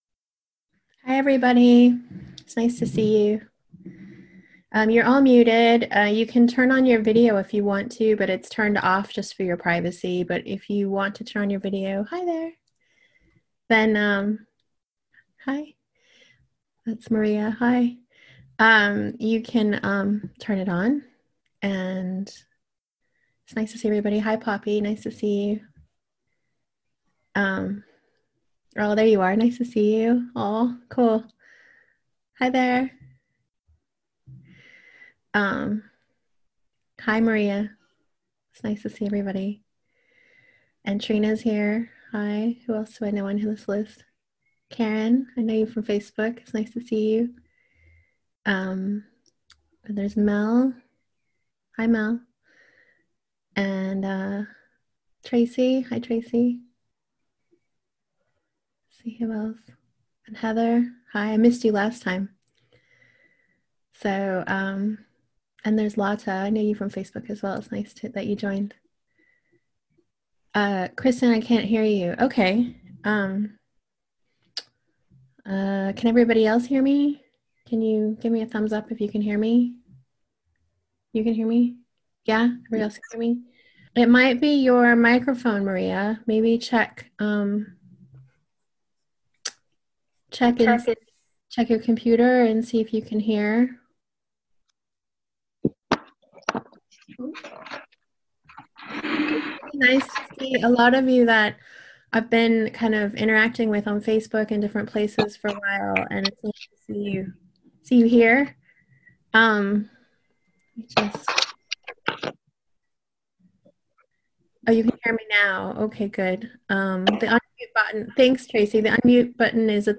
Boost-Your-Health-Medical-Intution-Talk-and-Guided-Meditation-4-April-2020.mp3